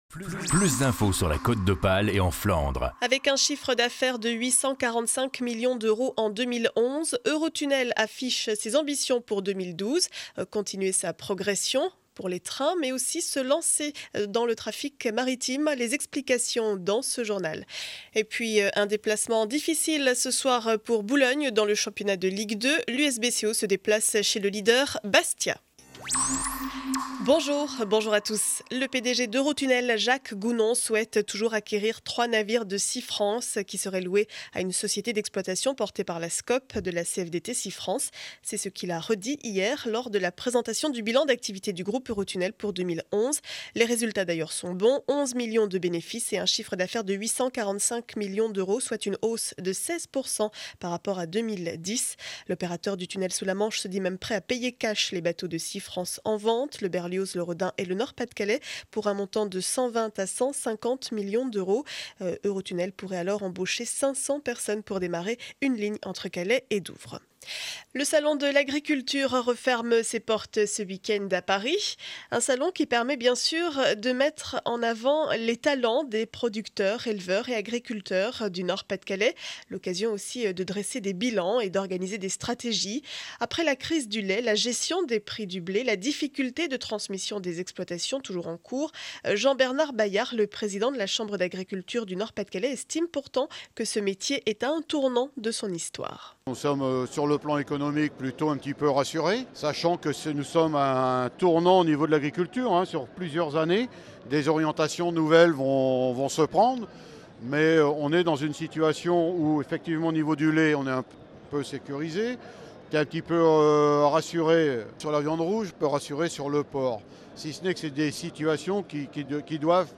Journal du vendredi 02 mars 2012 7heures 30 édition du Boulonnais.